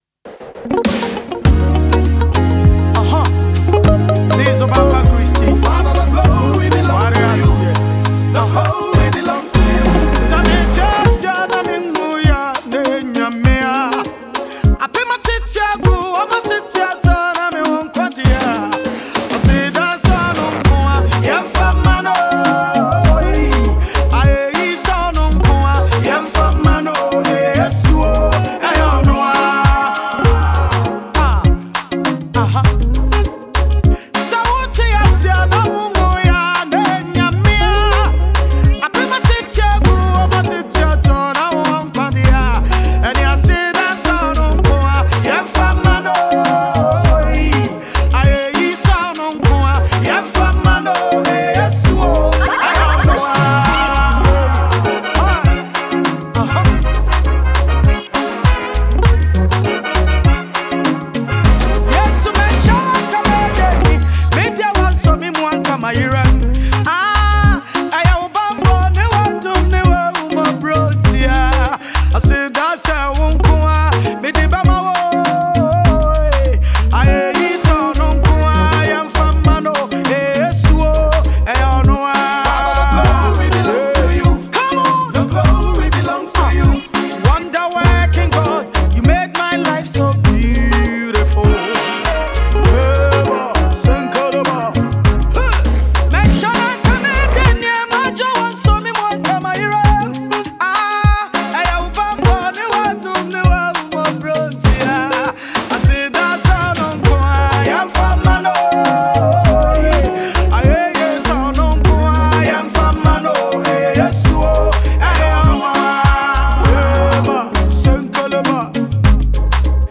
Gospel
a powerful gospel anthem
rich vocals shine over heartfelt lyrics
With a tender delivery and uplifting message